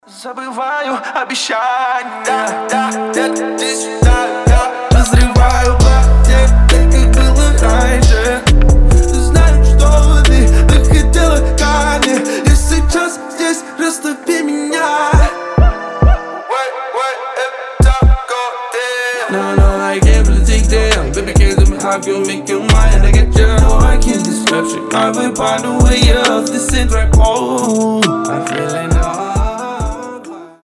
• Качество: 320, Stereo
мужской голос
Хип-хоп
басы